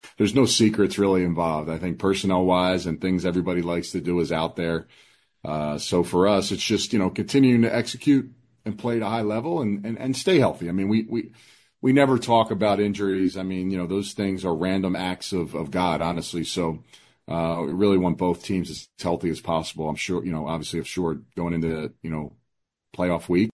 BH Head Coach Anthony Becht (says neither team can learn anything they don’t already know about the other playing this final regular season game)